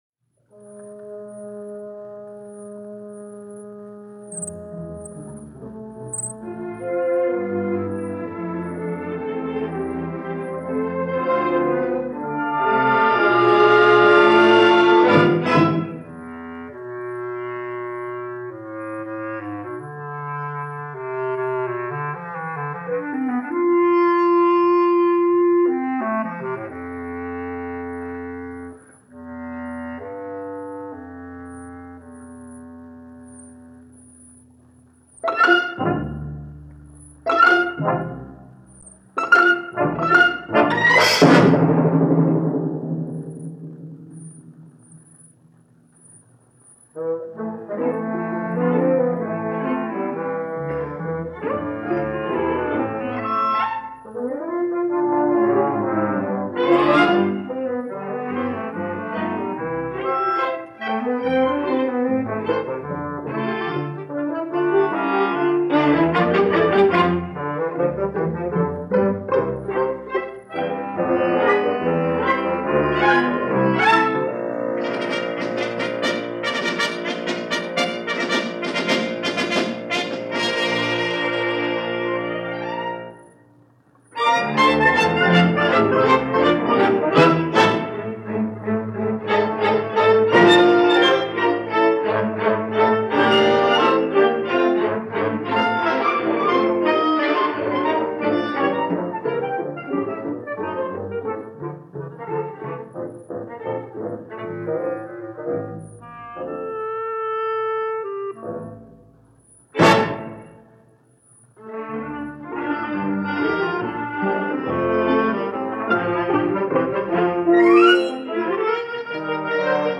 A Vagrom Ballad – NBC Studio Orchestra
Tonight it’s a 1945 broadcast recording by The NBC Studio Orchestra